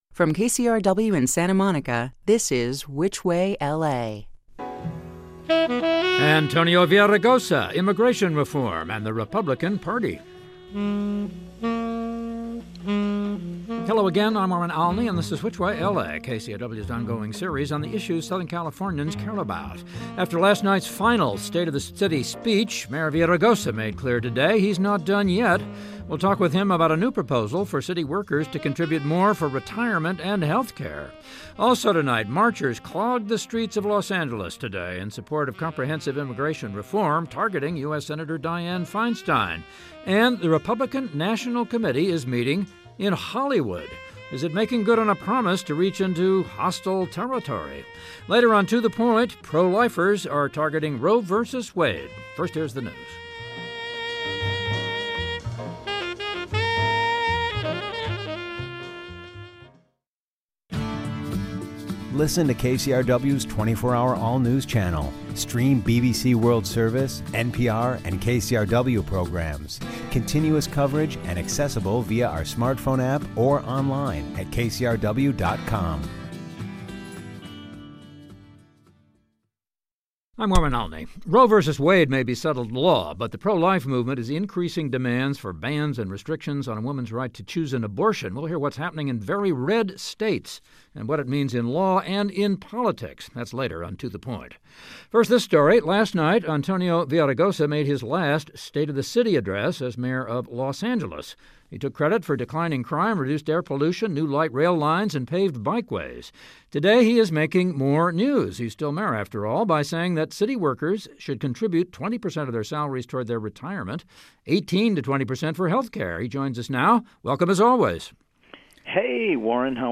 After last night's final state-of-the city speech, Mayor Villaraigosa made clear today that he's not done yet. We talk with him about a new proposal for city workers to contribute more for retirement and healthcare. Also, marchers clogged the streets of Los Angeles today in support of comprehensive immigration reform, targeting US Senator Dianne Feinstein.